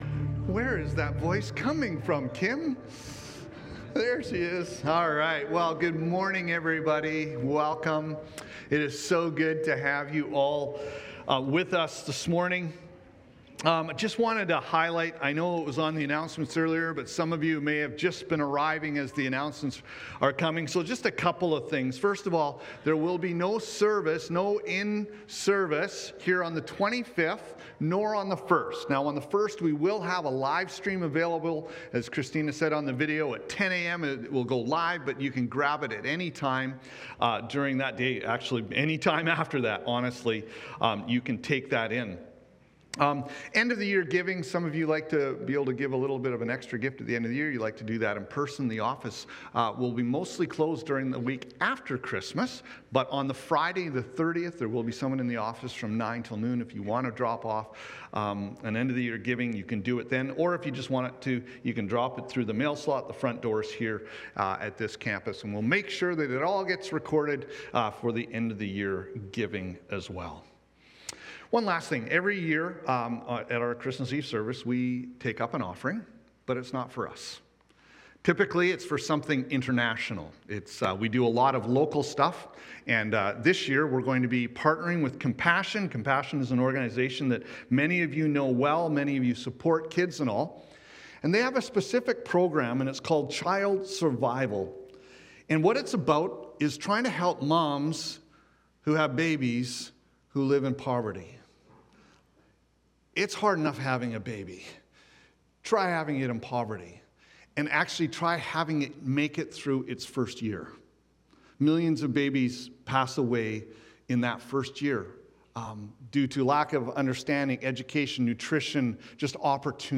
Behold Current Sermon Behold, the Light of the World!